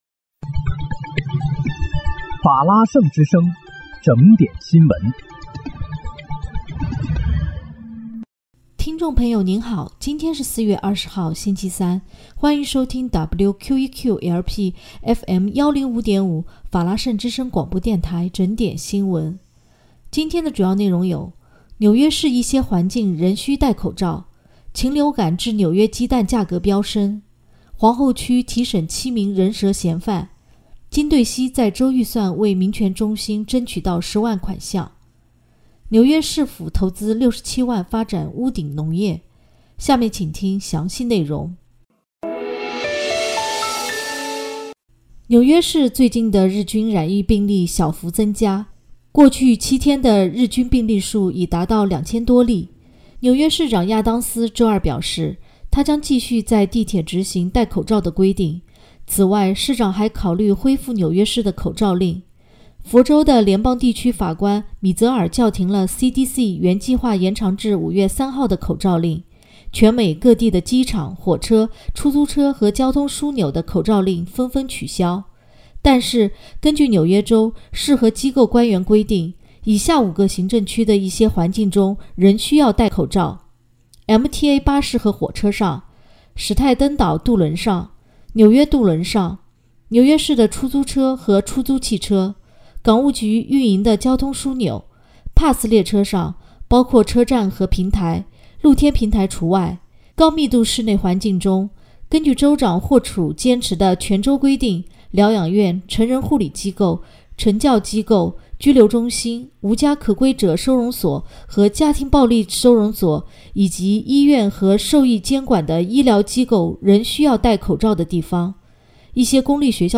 4月20日（星期三）纽约整点新闻